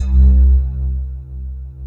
Index of /90_sSampleCDs/Big Fish Audio - Synth City/CD1/Partition B/05-SYNTHLEAD